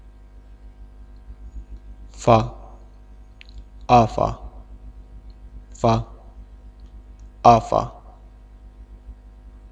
Consonnes - Sujet #4
f_fa_afa4_[24b].wav